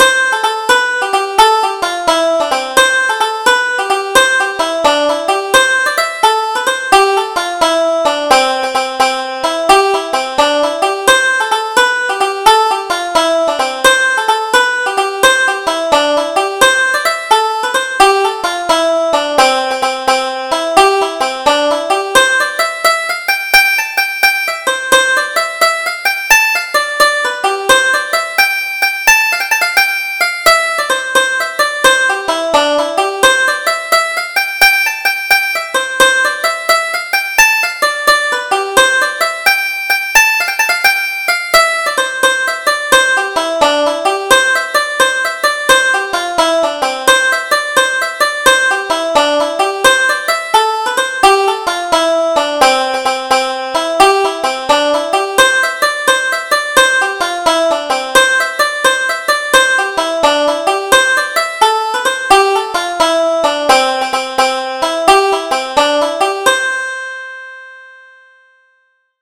Double Jig: The Night Dance